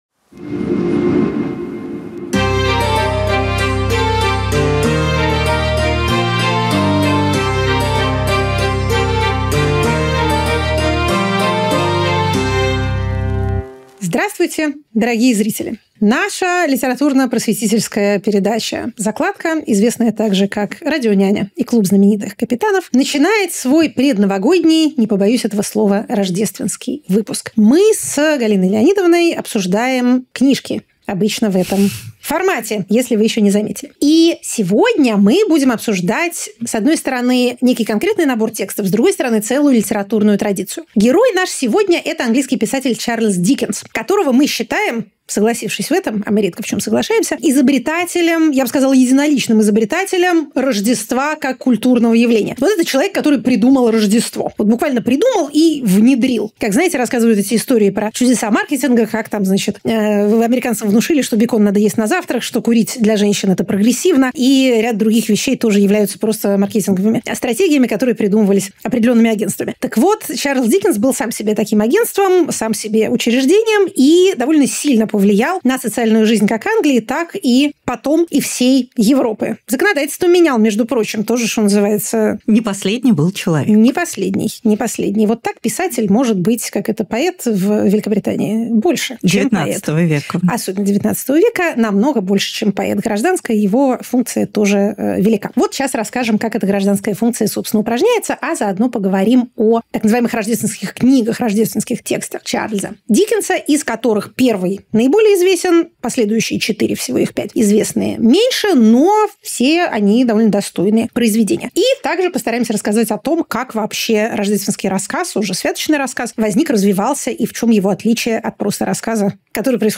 «Закладка»: Диккенс: человек, который придумал Рождество Галина Юзефович литературный критик Екатерина Шульман политолог Сначала он гастролировал по Англии, читая вслух и дополняя чтение искрометными выступлениями.